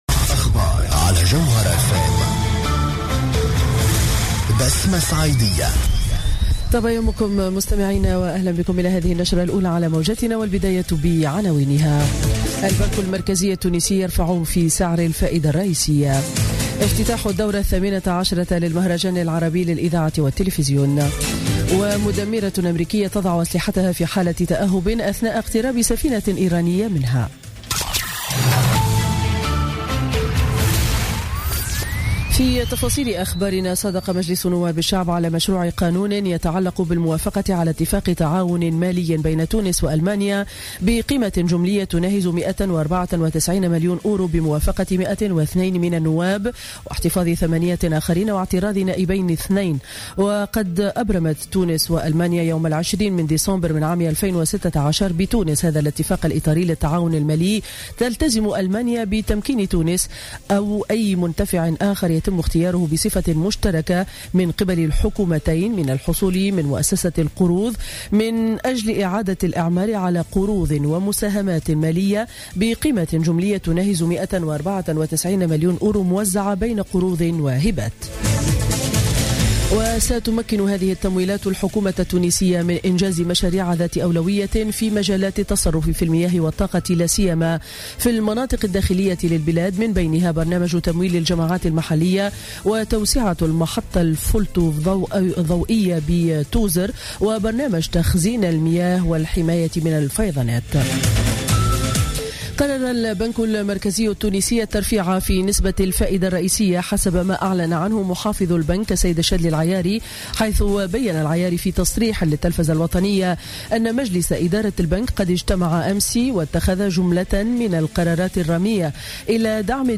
نشرة أخبار السابعة صباحا ليوم الإربعاء 26 أفريل 2017